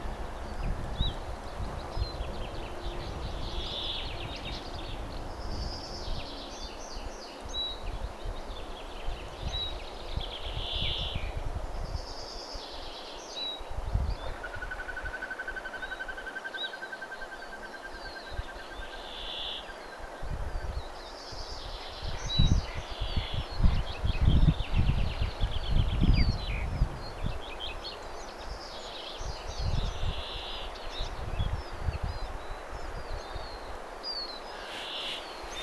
viy-tiltaltti,
tuollaisia vihervarpusen ääniä
viy-tiltaltti_tuollaisia_vihervarpusen_aania.mp3